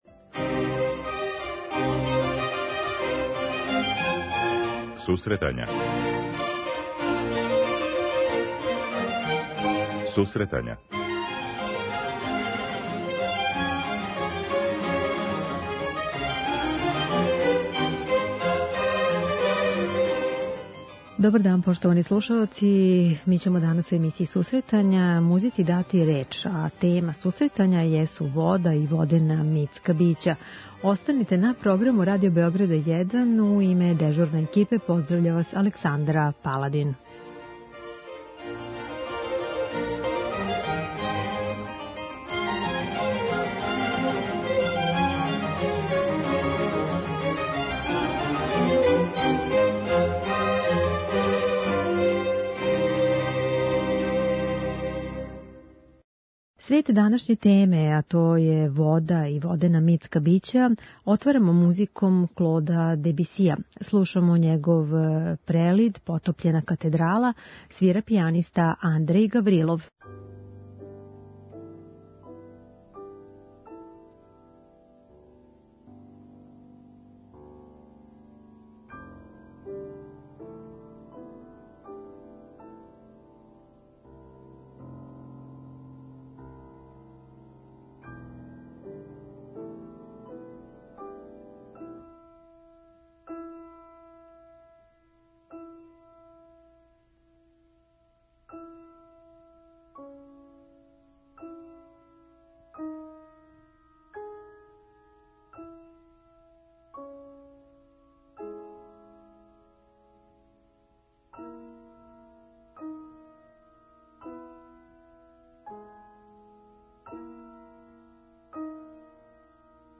У данашњој емисији слушаоци ће бити у прилици да чују композиције које су инспирисане управо овим темама. Биће то дела из опуса Клода Дебисија, Антоњина Дворжака, Мориса Равела и Бенџамина Бритна.